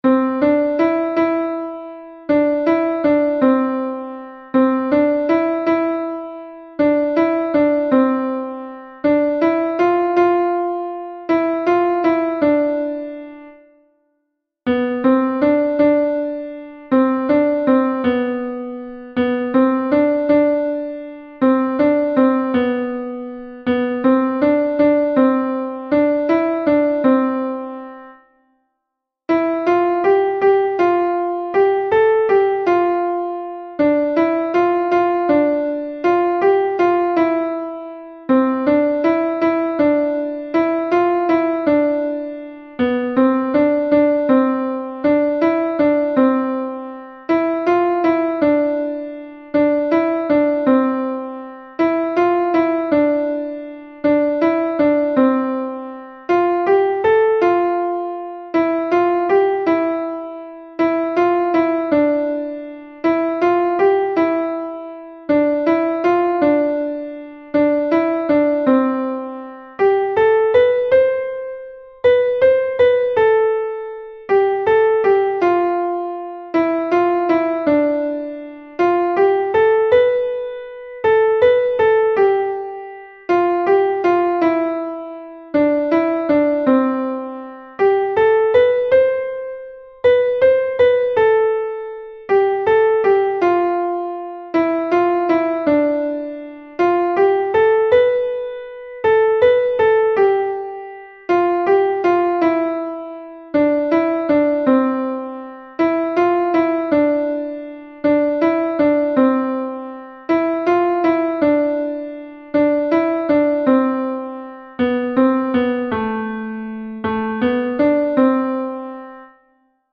Log of Persian music pieces I have composed